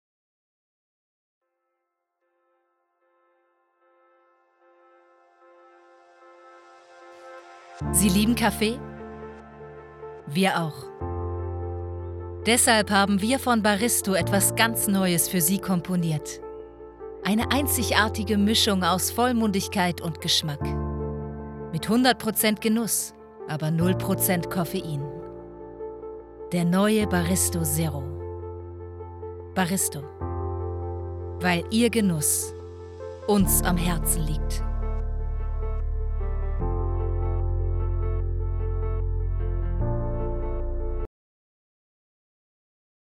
Vidéos d'entreprise
ContraltoHaute